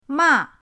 ma4.mp3